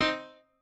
piano3_15.ogg